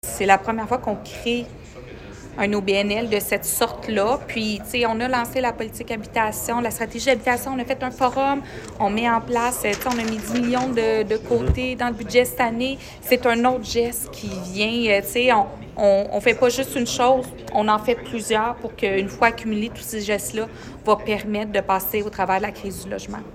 La mairesse de Granby, Julie Bourdon